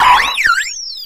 Audio / SE / Cries / SHAYMIN_1.ogg